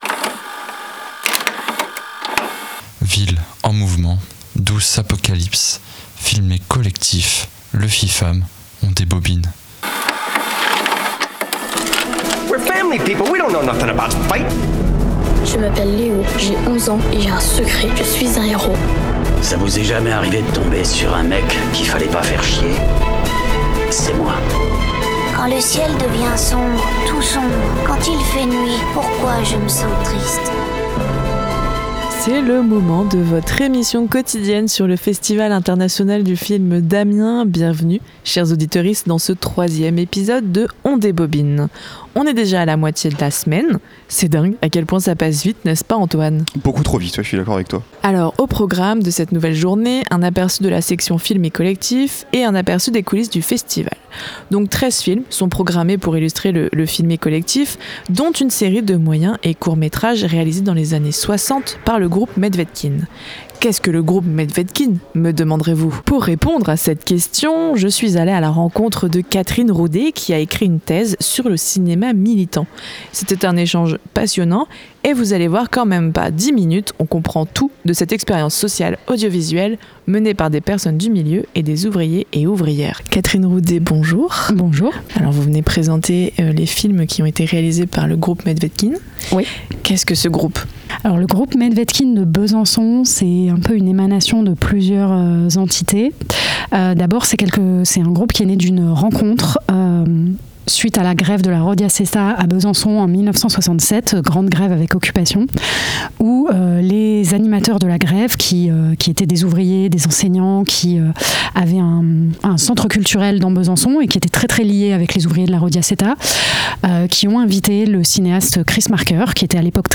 Bienvenue dans On débobine, l’émission spéciale autour du Festival International du Film d’Amiens (le FIFAM).